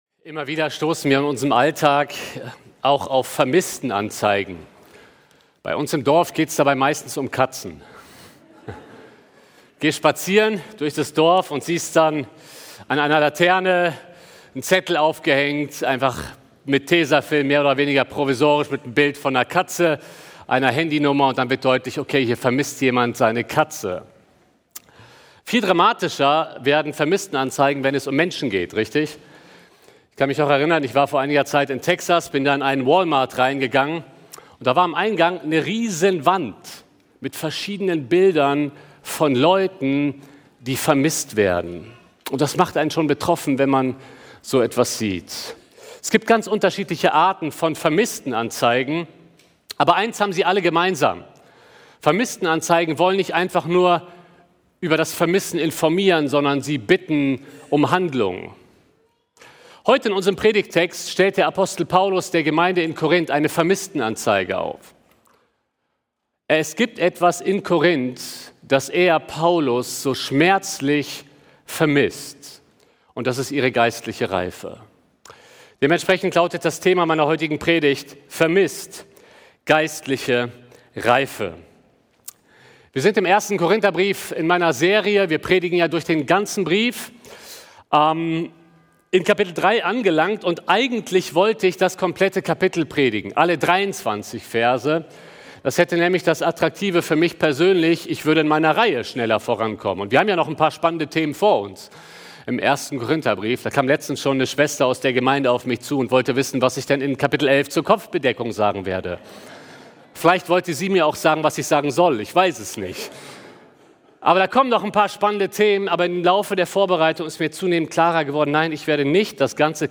November 2025 Predigt-Reihe: 1.